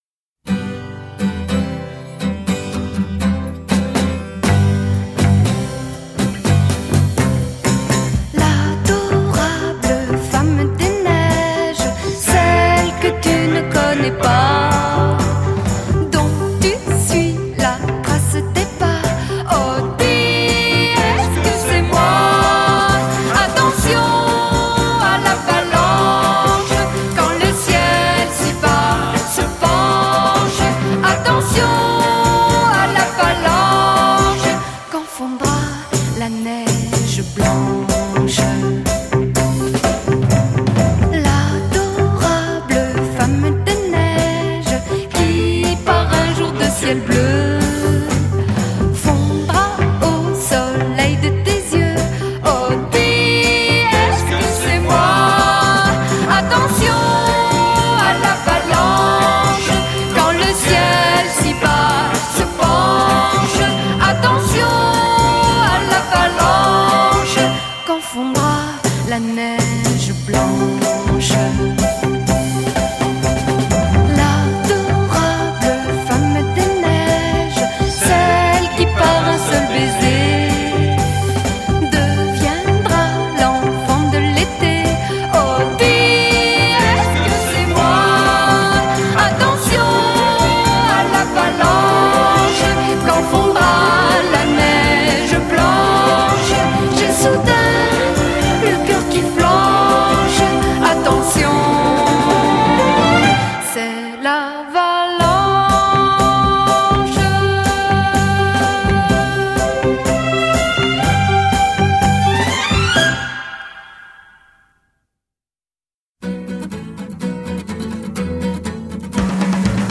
A taste of Yé-Yé tonight.
French singer
fuzz-guitar-heavy